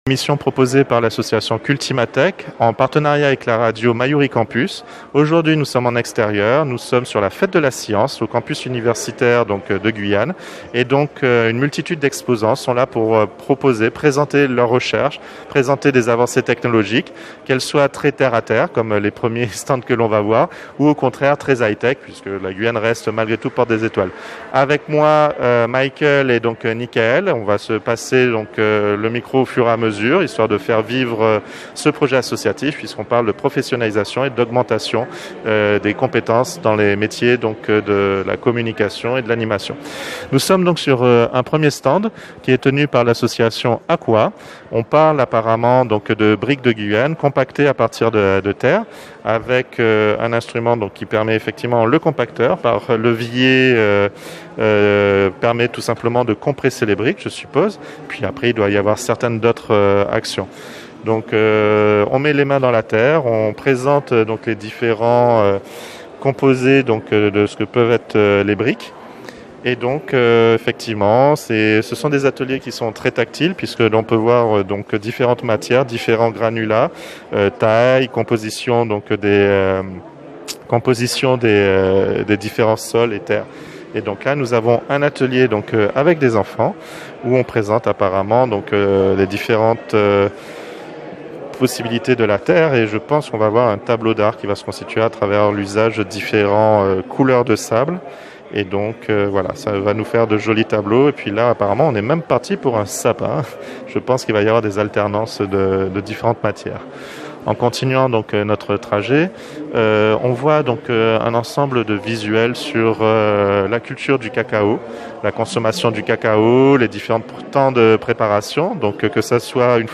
Troisième édition de la fête de la science à l'université de Guyane, reportage de Radio Mayouri
Reportage !